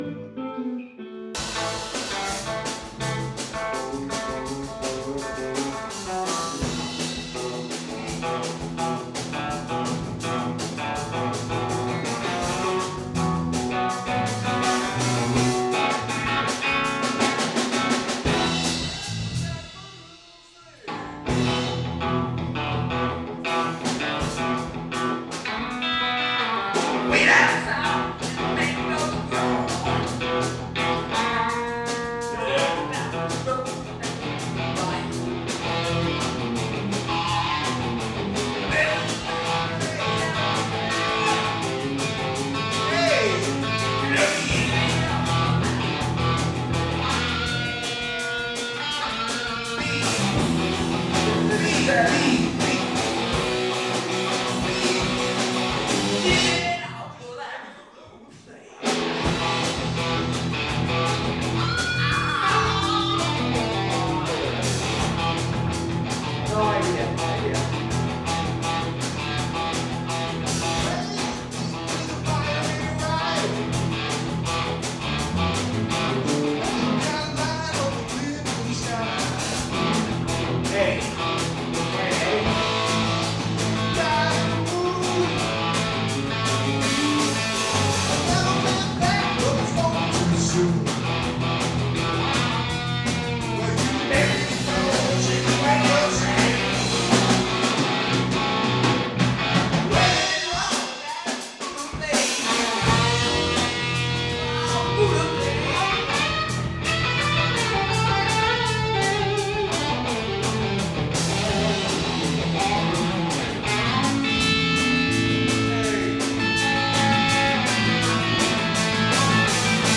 A bunch of friends got together again to make some noise.